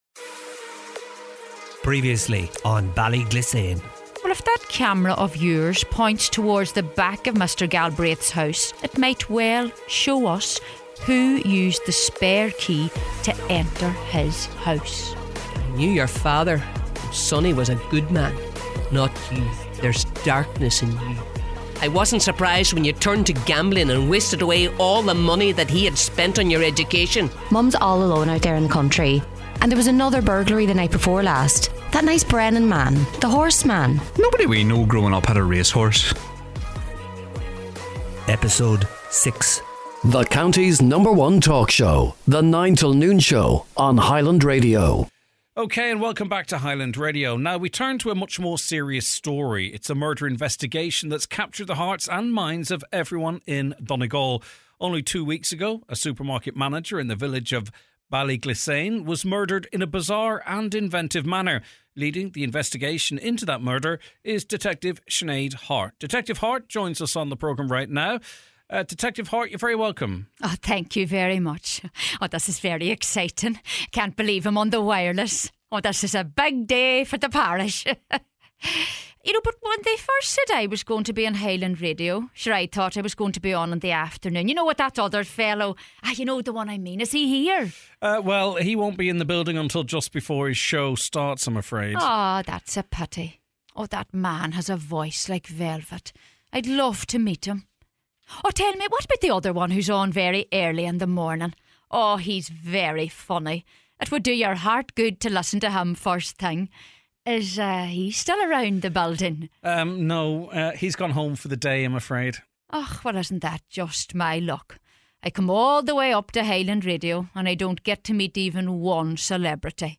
A drama series set in a small town somewhere in the heart of Donegal, where we meet various people who live in Ballyglissane and learn about their lives, their problems, and their secrets.